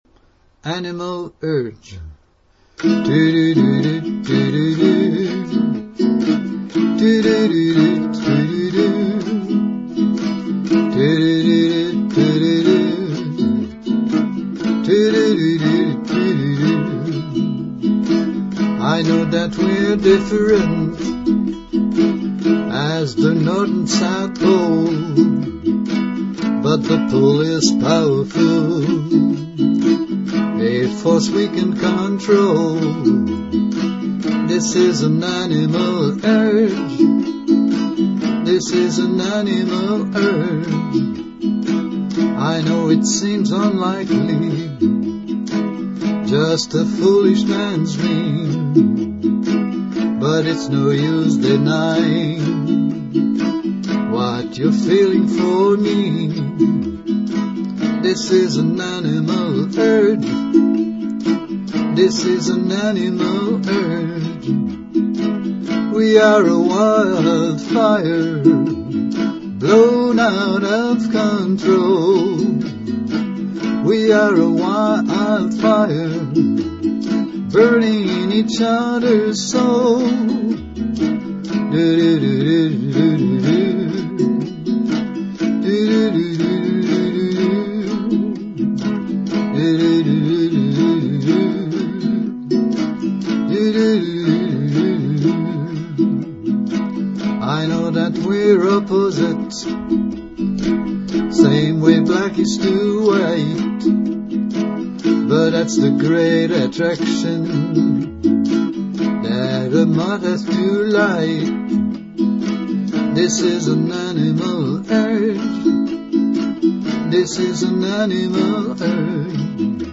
animalurgeuke.mp3
Intro: 8 bars (key of E)